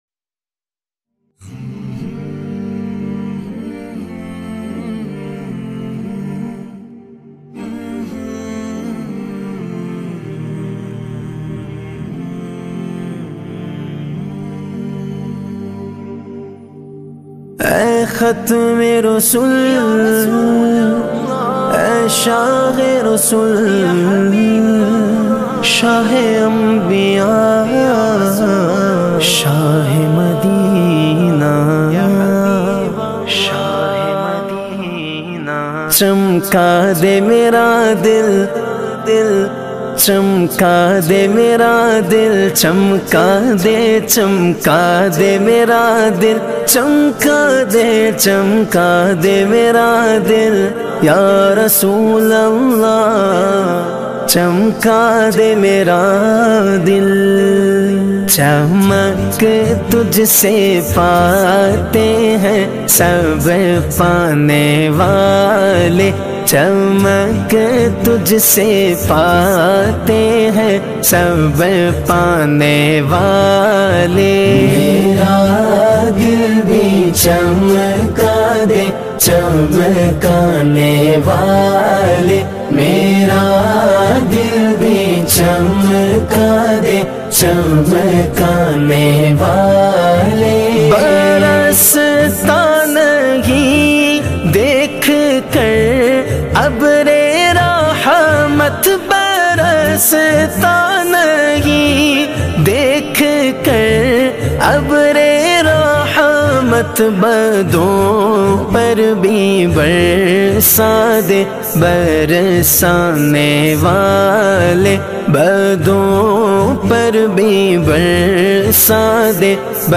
Beautifull Naat